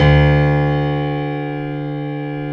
55p-pno08-D1.wav